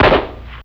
Rifle.wav